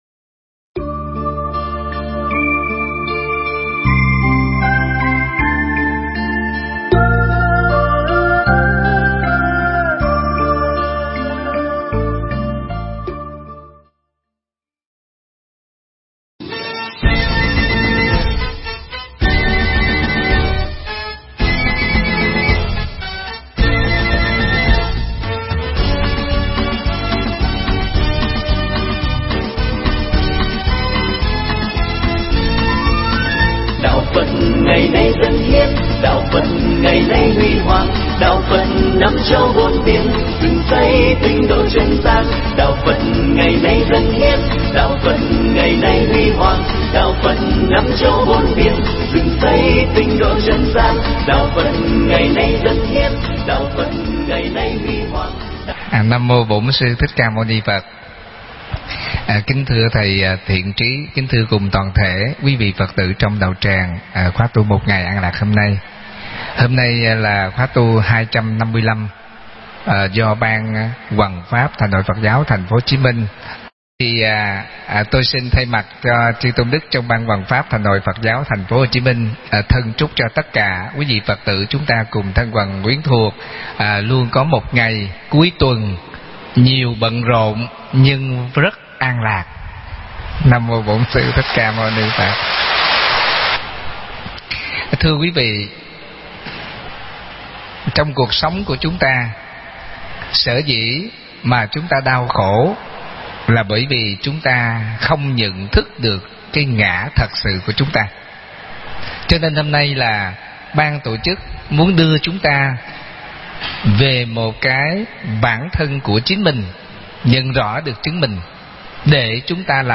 Nghe Mp3 thuyết pháp Truy Tìm Tự Ngã